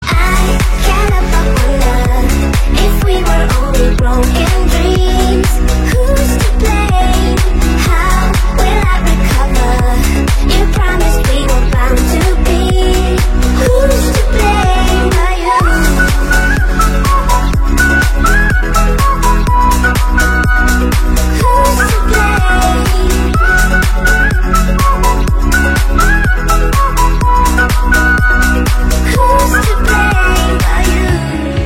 Kategorien POP